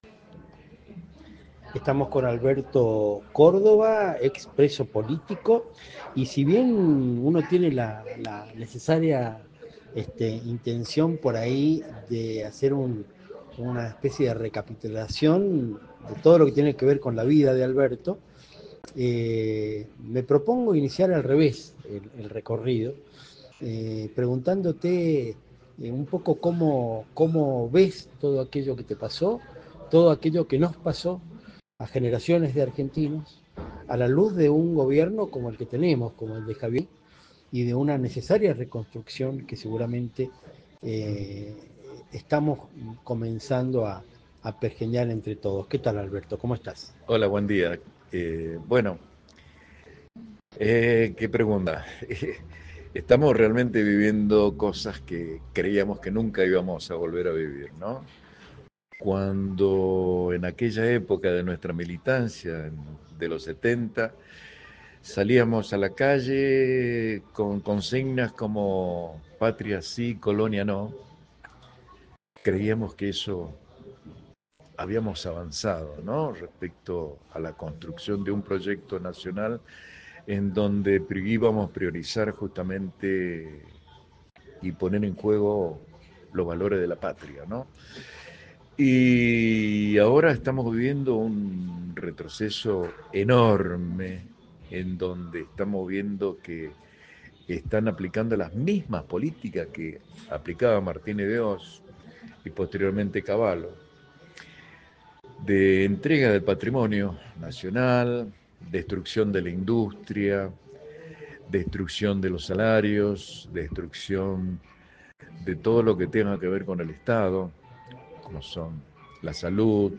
Entrevistas militantes
Su discurso, inteligente y a la vez celebratorio de la importancia de involucrarnos en la lucha por un mundo mejor, no deja lugar a dudas.